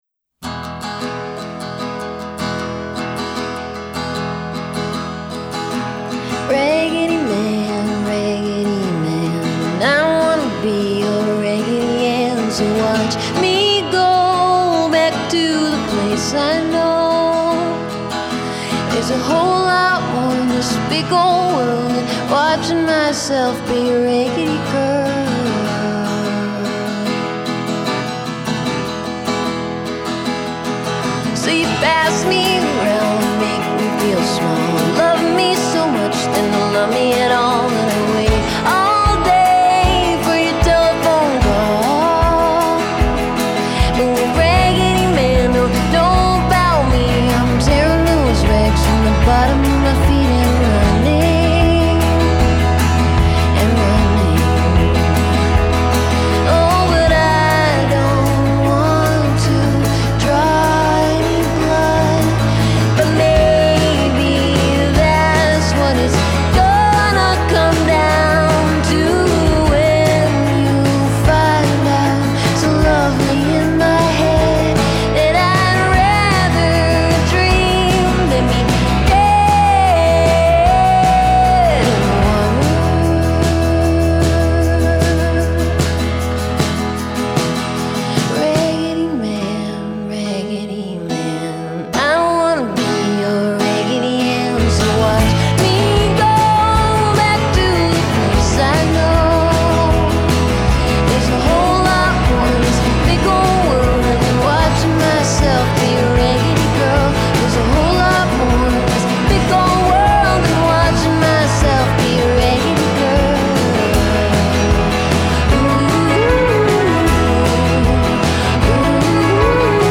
Delightful, succinct, Neko-ish
Her two primary tricks are melodic.
The other melodic trick is her 16-measure melody line.